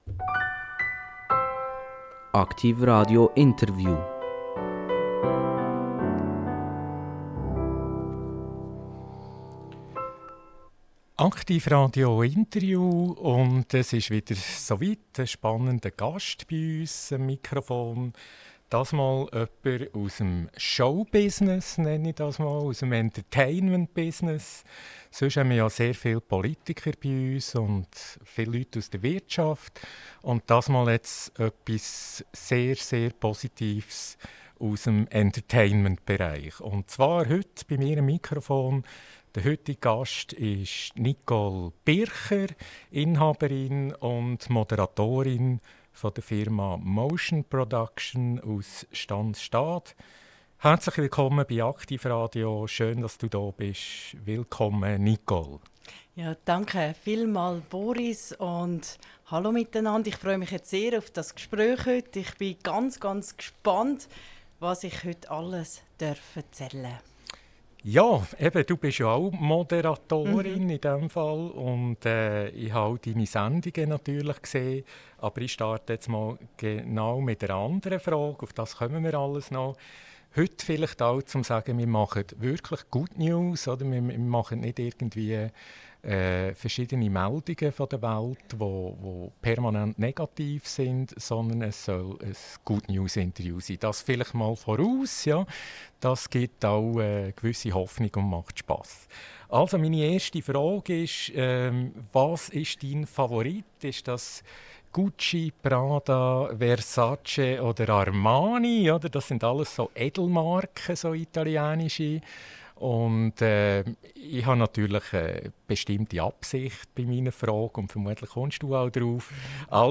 INTERVIEW - Stefanie Ingold - 07.04.2025 – AKTIV RADIO – Lyssna här